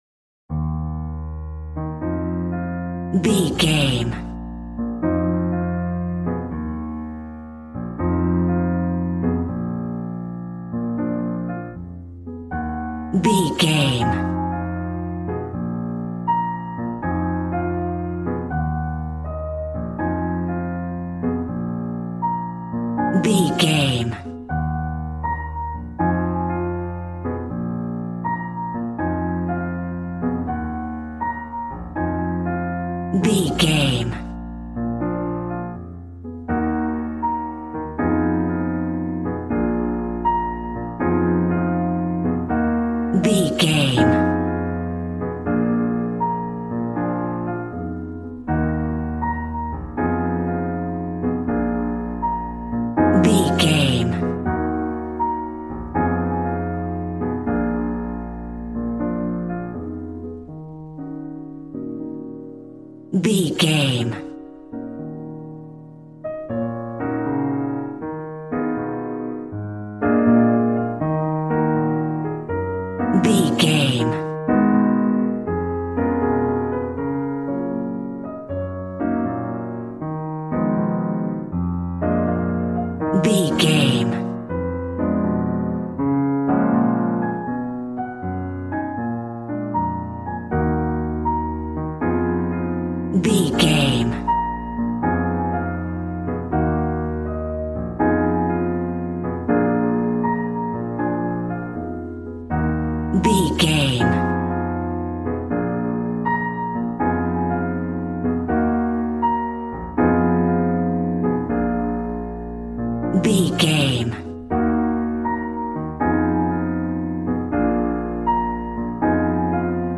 Smooth jazz piano mixed with jazz bass and cool jazz drums.,
Aeolian/Minor
B♭
drums